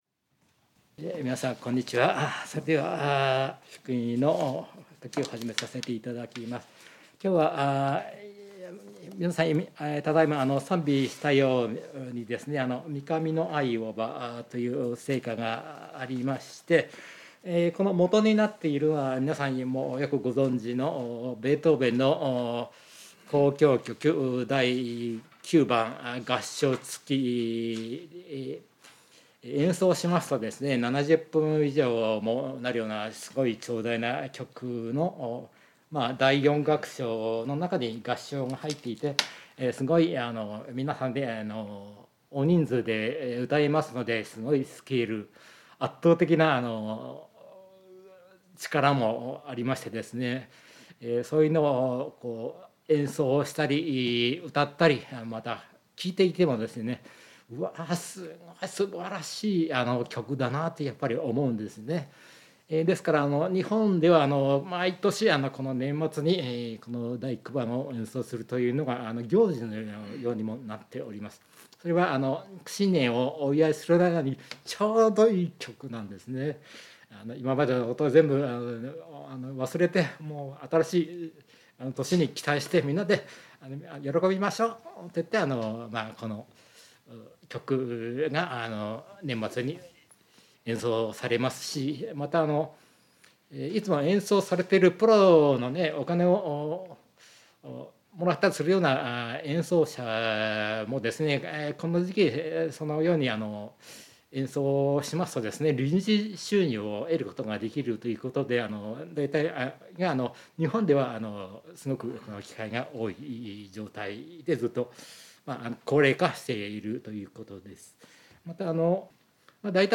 聖書メッセージ No.248